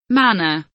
manner kelimesinin anlamı, resimli anlatımı ve sesli okunuşu